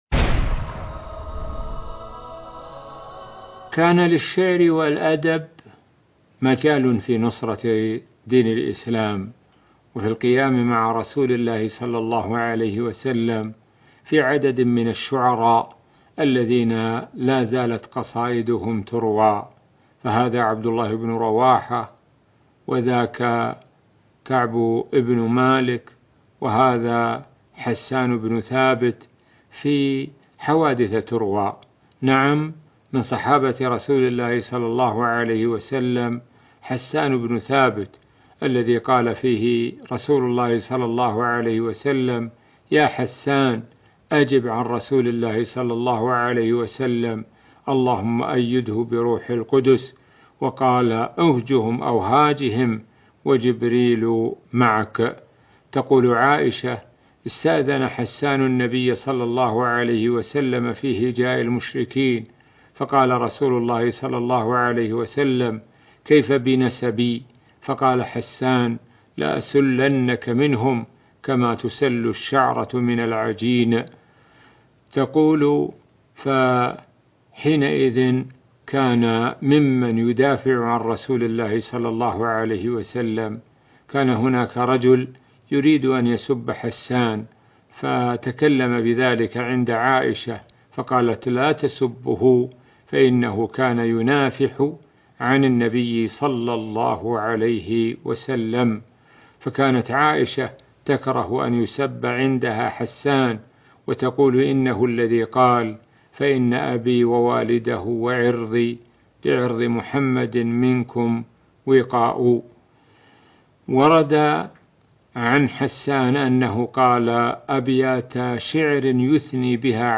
عنوان المادة د سعد الشثري يتحدث عن الصحابي حسان بن ثابت كان يدافع بشعره عن الرسولﷺ